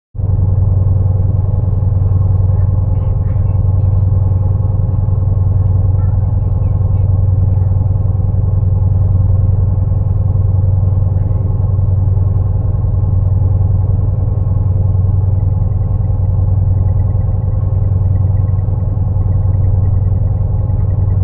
The ship is powered by a pair of EMD 12-710 diesel engines rated at 3000 hp each at 900 rpm, or 15 Hz.
I took a sound measurement with my Android Smartphone near the air vents, which are excellent conductors of engine room sound. The fundamental acoustic frequency is 10.4 Hz which is about 69% of full power.
Integer harmonics of 10.4 Hz are also present. The highest individual peak is at 93.4 Hz which is nine times 10.4 Hz.